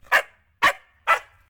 sounds_puppy_bark.ogg